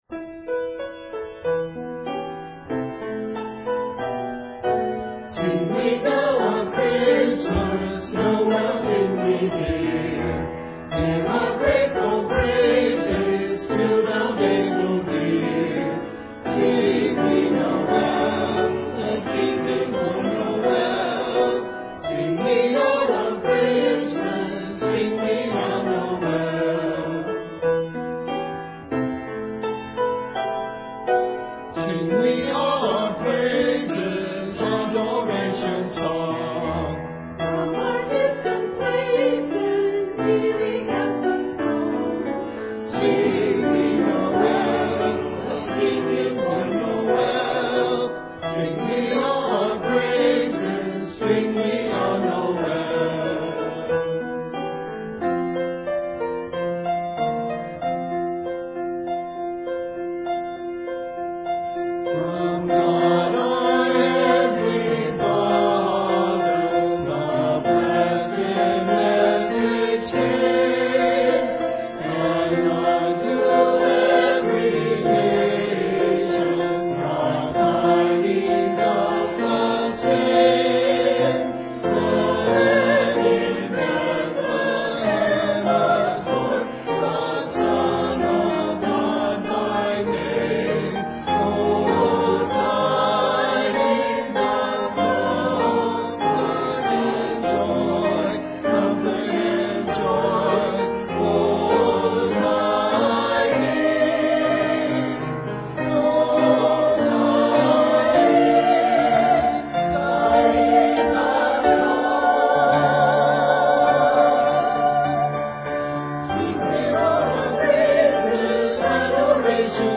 Sunday Service
Christmas Cantata
(Note, due to some music overlapping naration, some parts are combined)